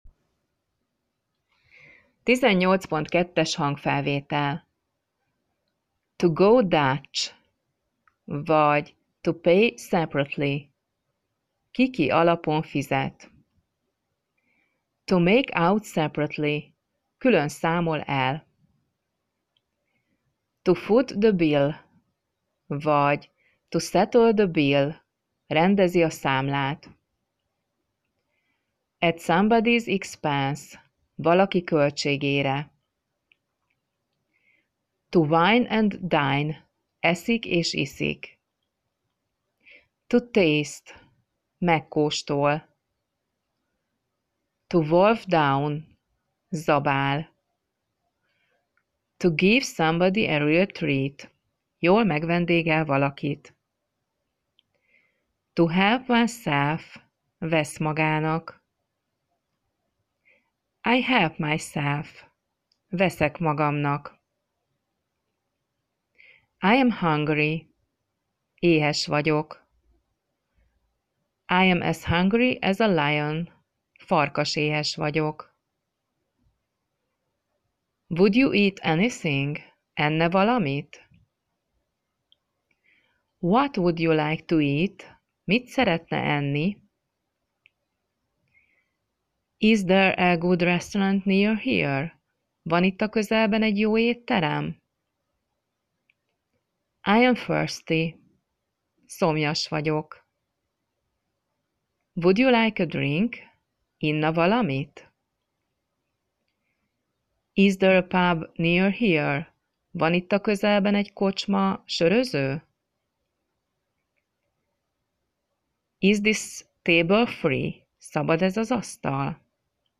lassan, tagoltan, jól artikuláltan, kellemes hangon mondja el a szavakat, kifejezéseket, mondatokat